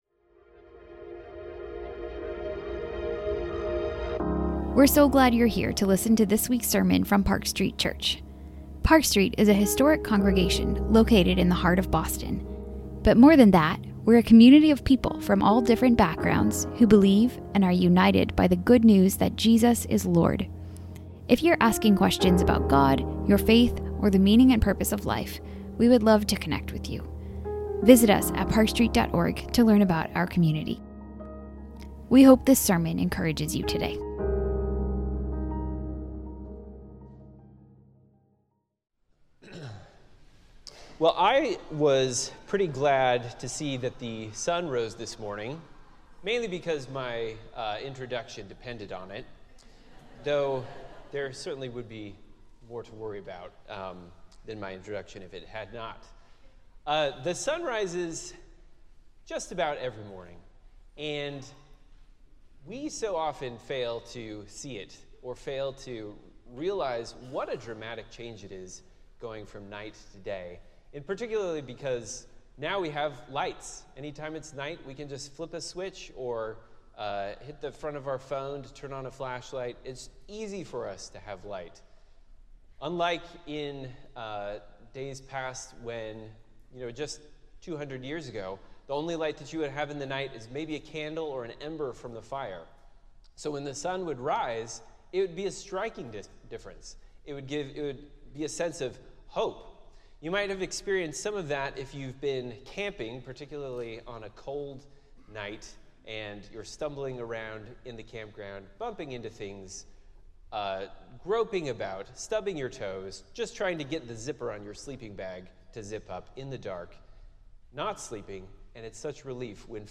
Weekly sermons from Boston's historic Park Street Church